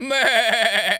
goat_baa_stressed_hurt_04.wav